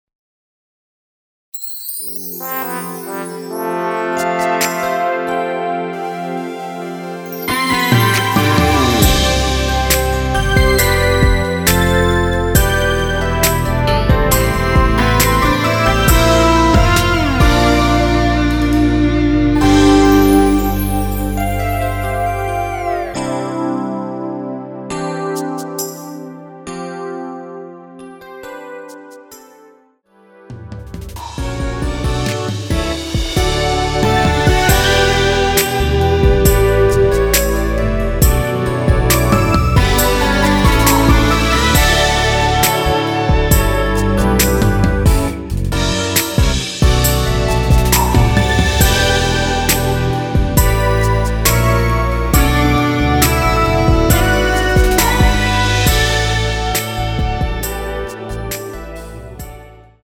원키에서(-7)내린 MR입니다.
앞부분30초, 뒷부분30초씩 편집해서 올려 드리고 있습니다.